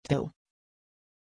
Pronuncia di Teo
pronunciation-teo-sv.mp3